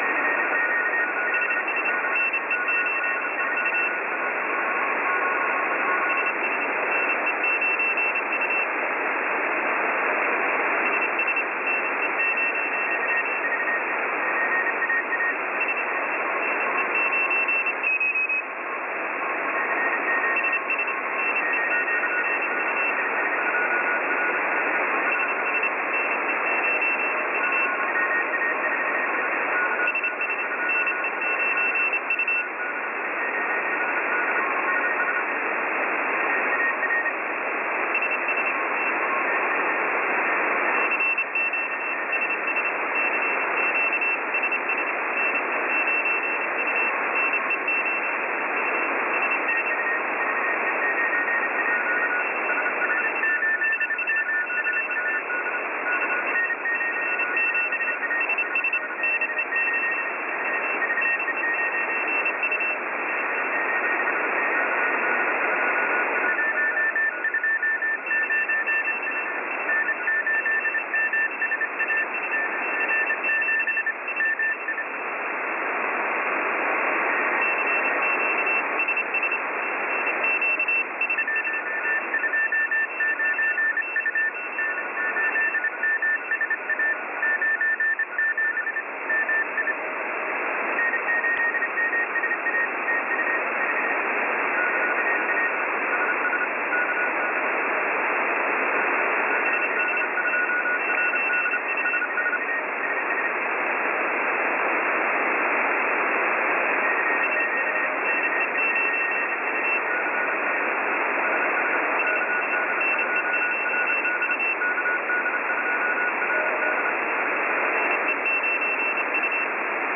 描述：火腿无线电莫尔斯电码在短波电台播出。
用特温特大学的在线广播接收器拿起并录制。
标签： 火腿无线电 短波 无线电 莫尔斯代码 哔哔声 上午 莫尔斯 火腿 业余 代码 业余无线电
声道立体声